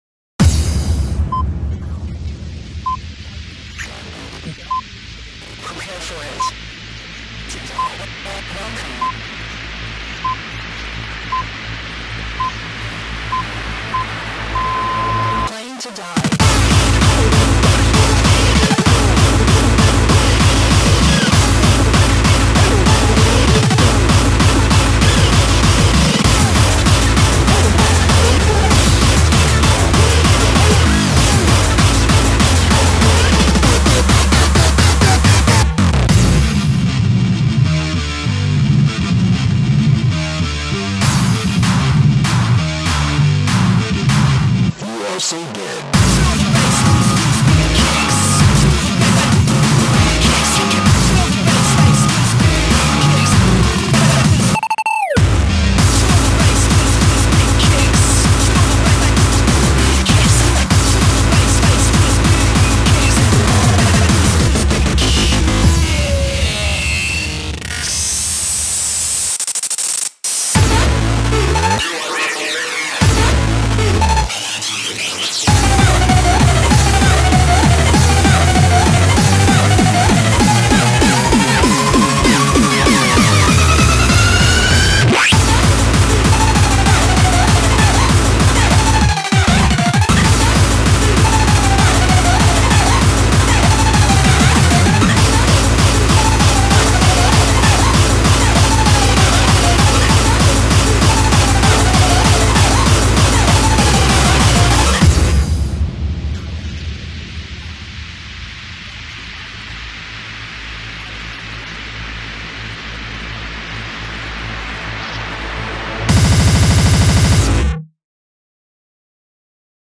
BPM49-390
Audio QualityPerfect (High Quality)
Comments[INDUSTRIAL]